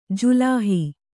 ♪ julāhi